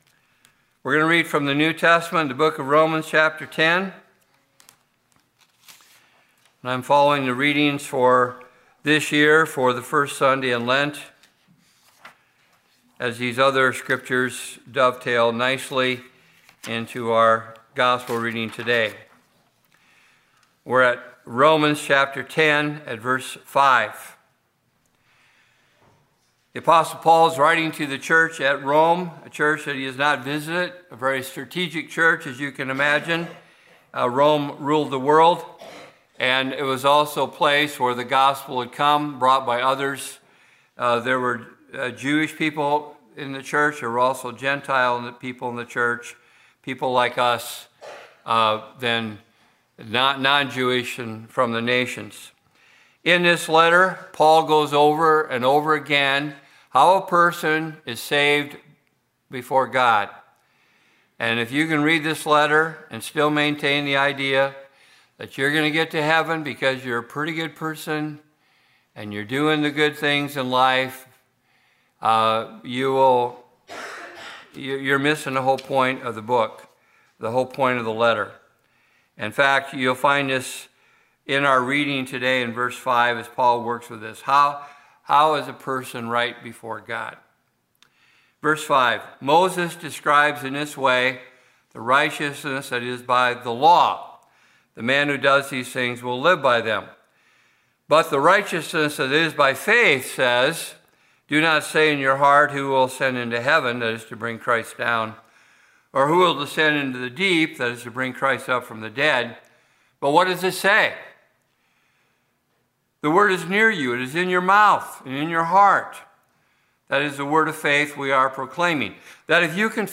Technical note: A portion of audio was lost at the start of the sermon,